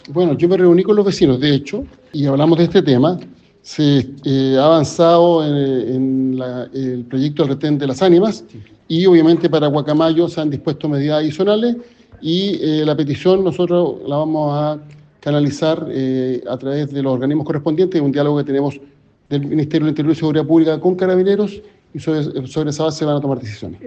Ante ello, el ministro Elizalde señaló que tras conversar con los vecinos, comprometió que analizarán esta petición junto con Carabineros, para desde ahí tomar decisiones.